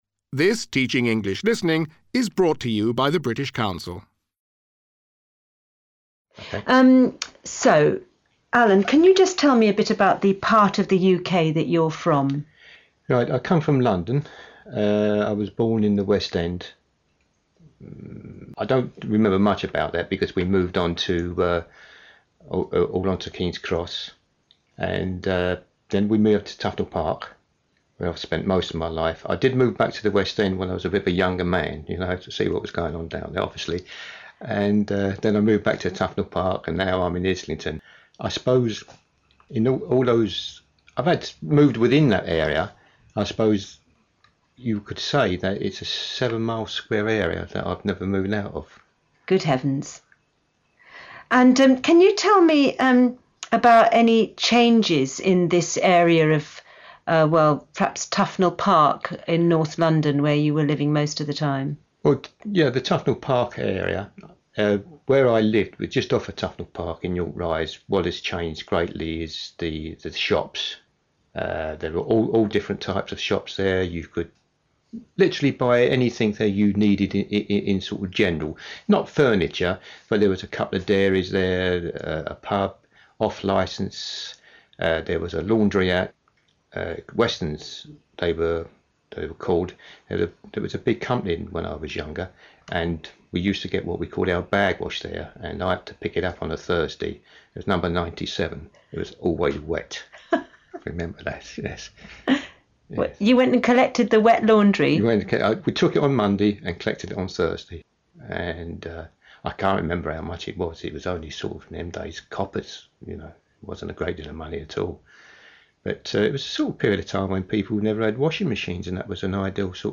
In this lesson students hear a man being interviewed about his life in London. He talks about the different parts of London he has lived in and how things have changed in these areas.